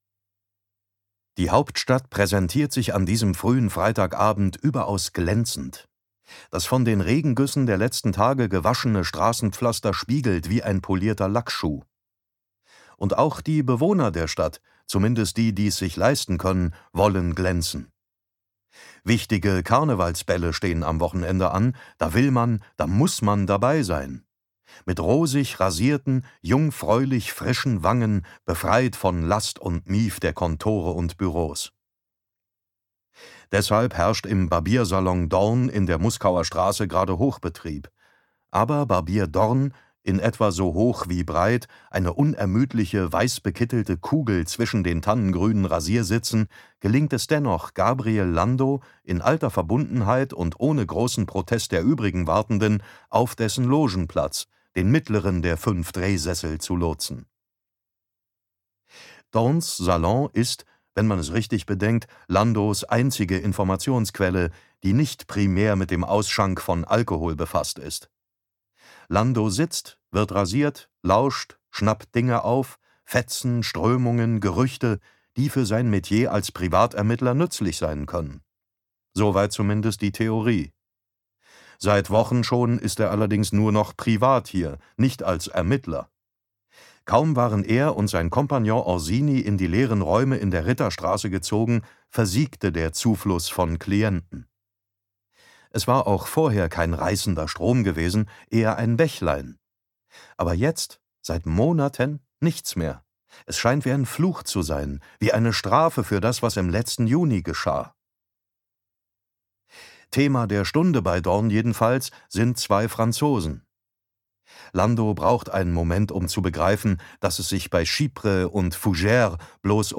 Kriminalistische Neuigkeiten aus dem Deutschen Kaiserreich – Band Zwei der blutigen historischen Krimireihe mit Hörbuch-Sprecher David Nathan
Gekürzt Autorisierte, d.h. von Autor:innen und / oder Verlagen freigegebene, bearbeitete Fassung.
Goldtod Gelesen von: David Nathan